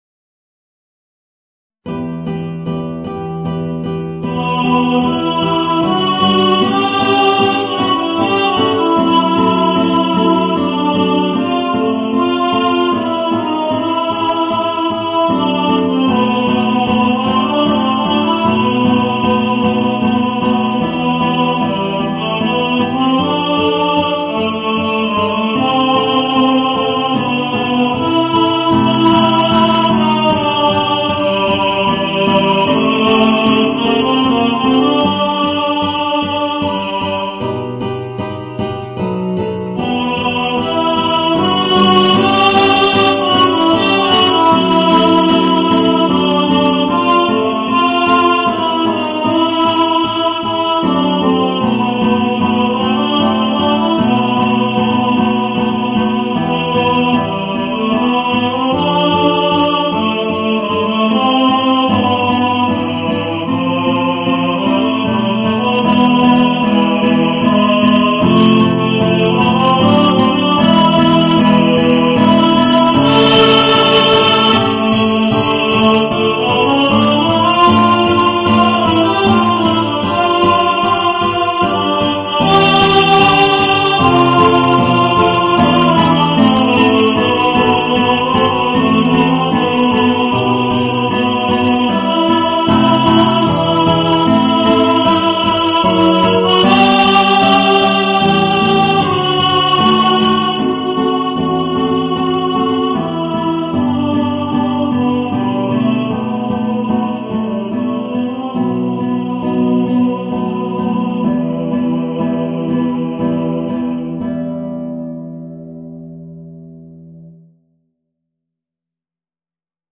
Voicing: Guitar and Tenor